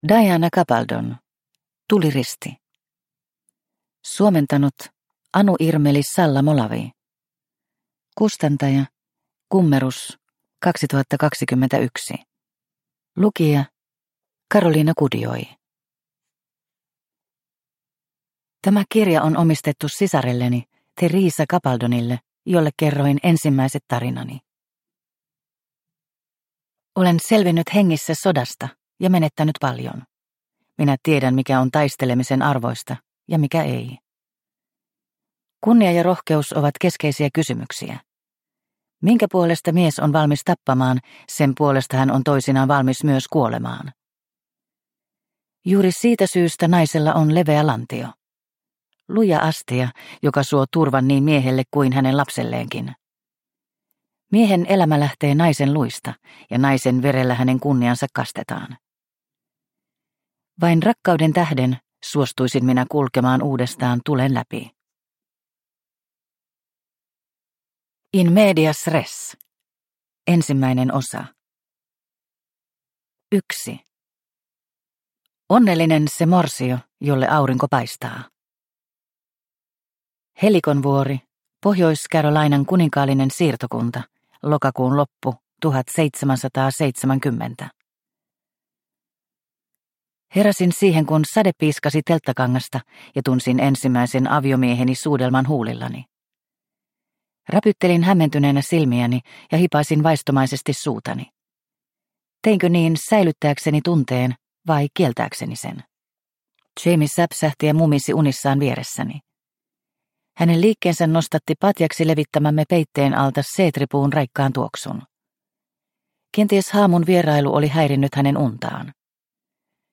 Tuliristi – Ljudbok – Laddas ner